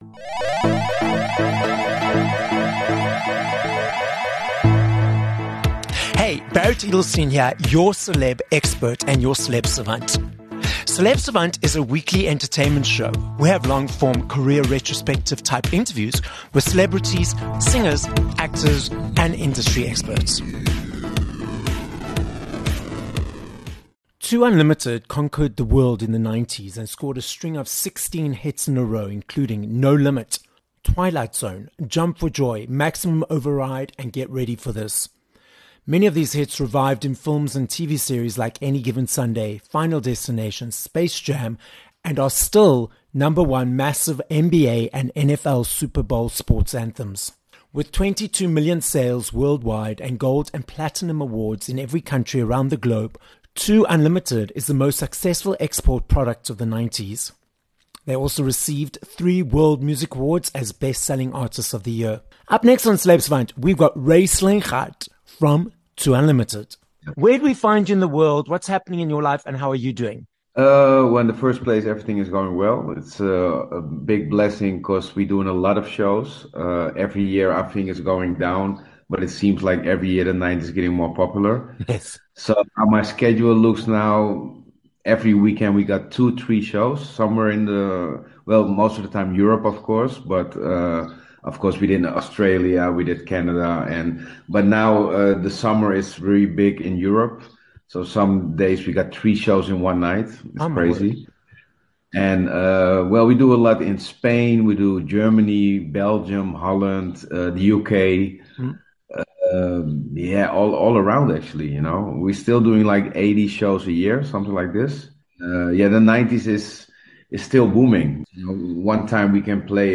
17 Sep Interview with Ray Slijngaard (2 Unlimited)